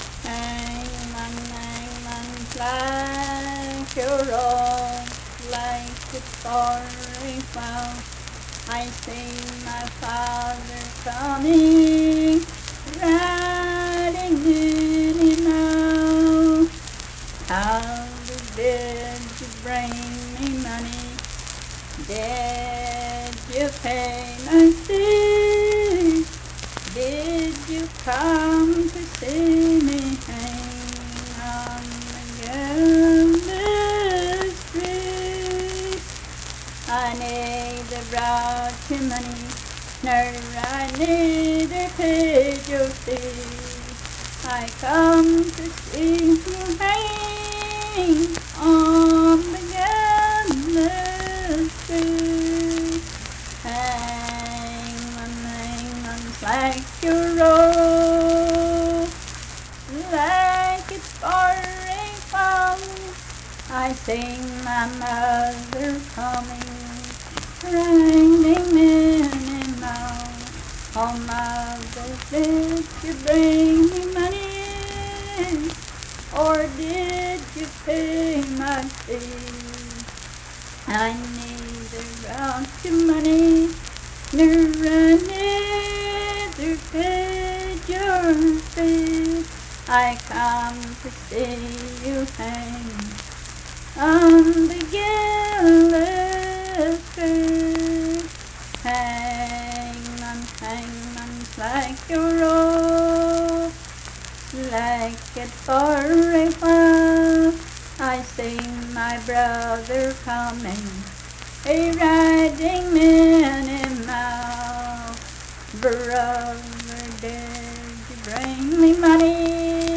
Unaccompanied vocal music
Verse-refrain 9(12w/R).
Performed in Big Creek, Logan County, WV.
Voice (sung)